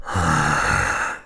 sounds_zombie_growl_01.ogg